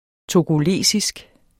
togolesisk adjektiv Bøjning -, -e Udtale [ togoˈleˀsisg ] Betydninger fra det vestafrikanske land Togo; vedr.